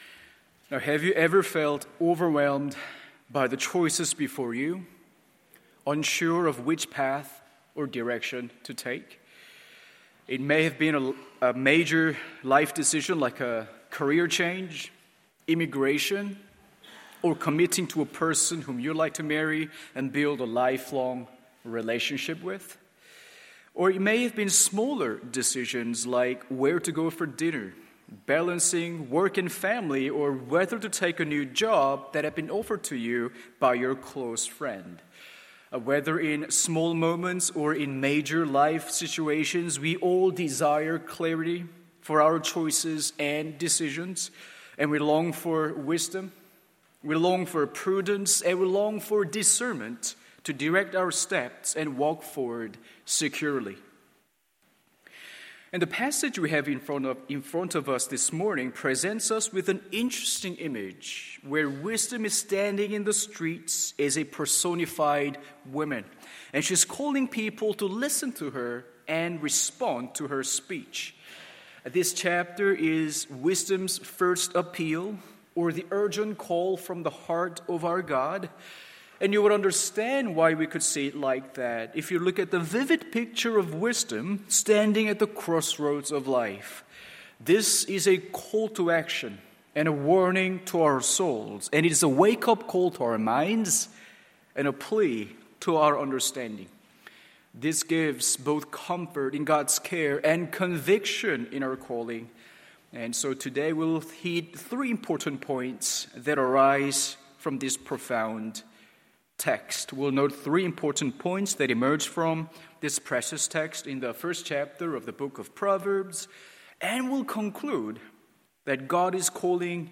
MORNING SERVICE Proverbs 1:20-33…